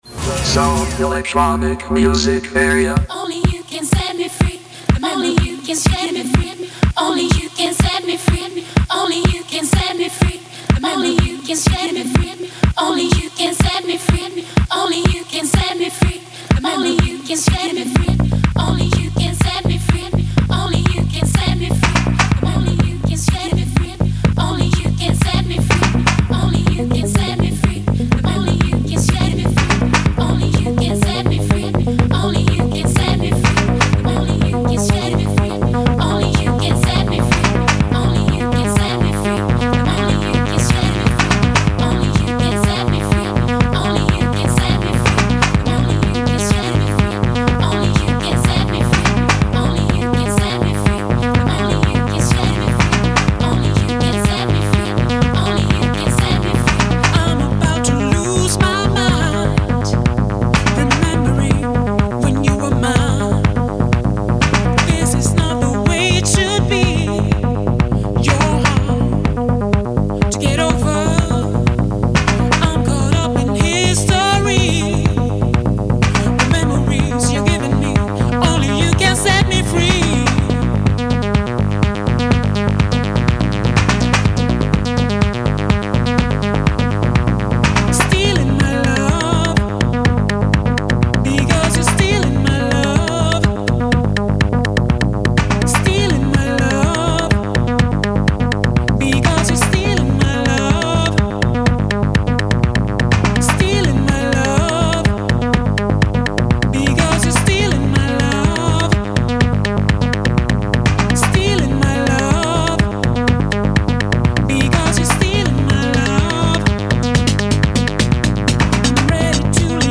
Live show with NEW Promo & Upcoming Releases
Special INTERVIEW + DJset Onair Live